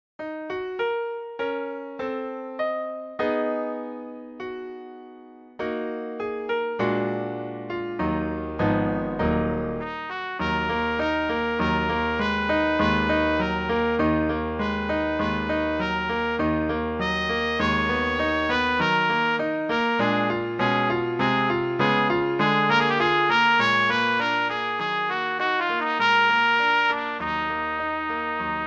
Přednesová skladba pro trubku
klavírní doprovod